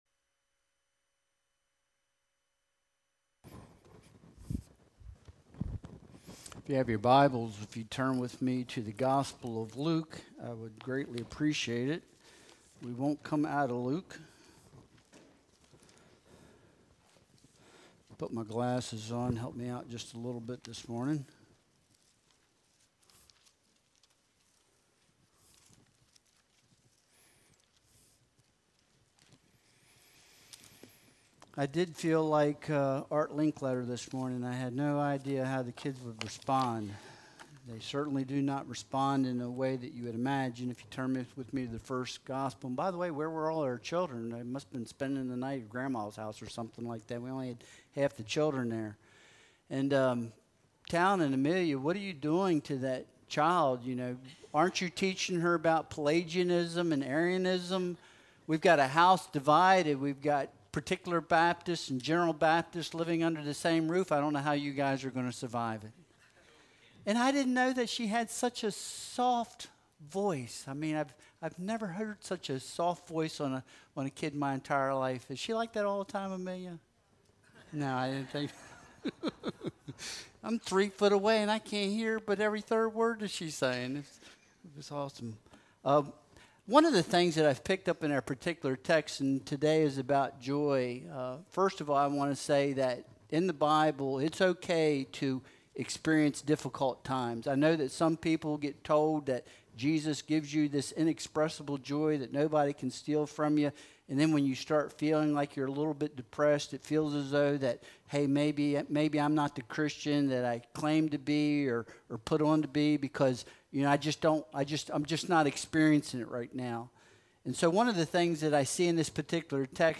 Luke 1.39-45 Service Type: Sunday Worship Service Download Files Bulletin Topics